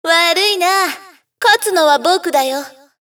サンプルボイス